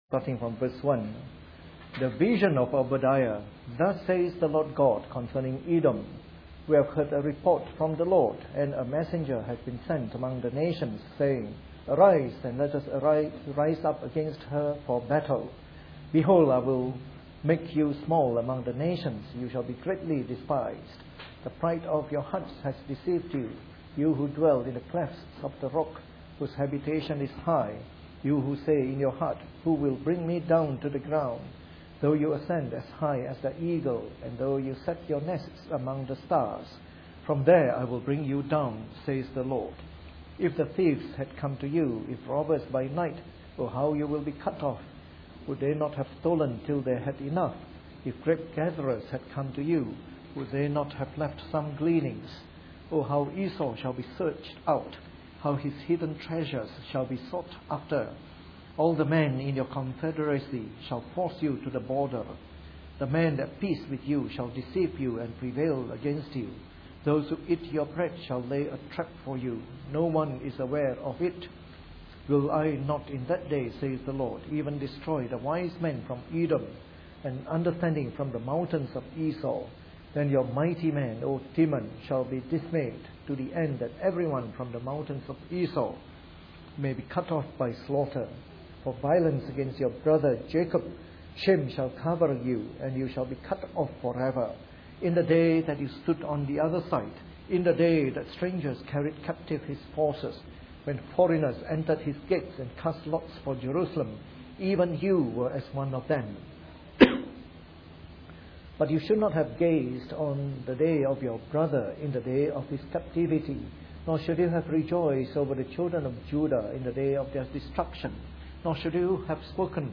Preached on the 21st of November 2012 during the Bible Study, from our series on “The Minor Prophets.”